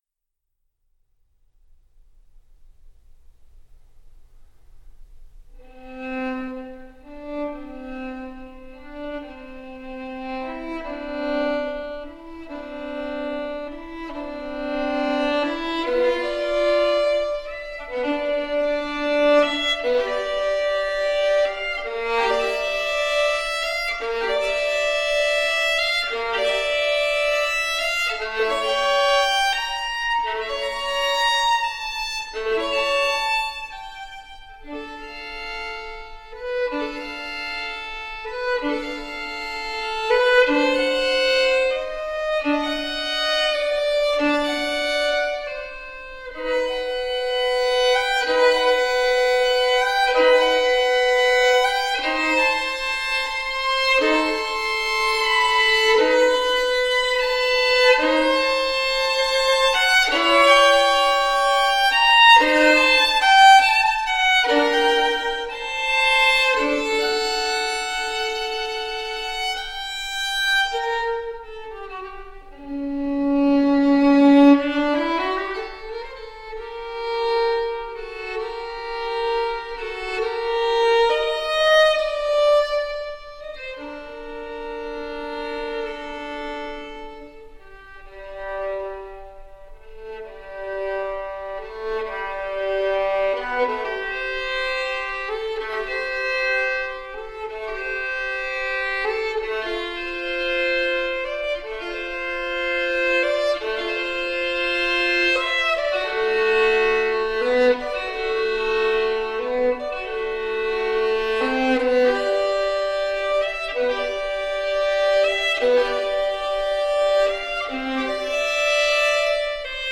so the resulting music sounds incredibly fresh and alive.
Classical, Baroque, Instrumental